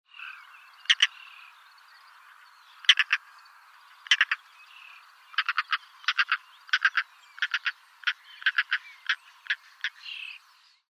نام فارسی : سبز قبا
نام انگلیسی :European Roller